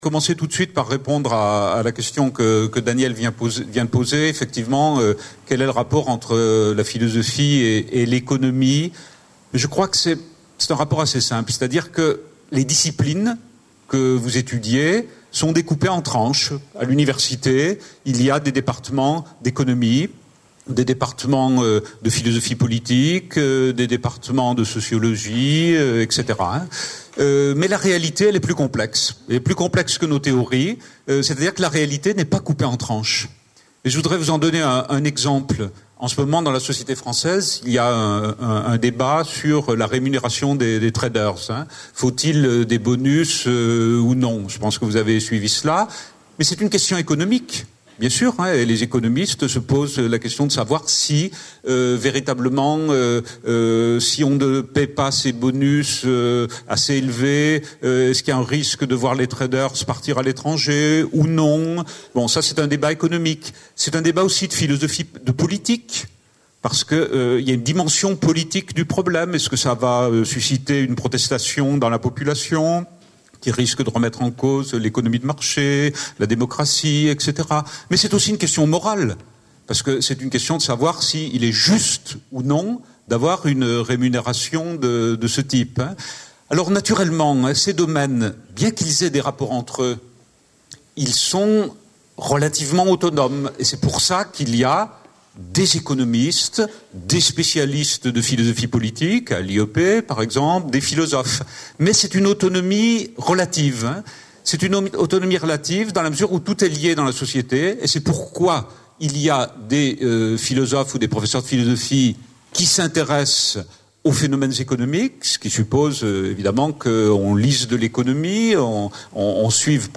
Une conférence de l'UTLS au Lycée Europe et mondialisation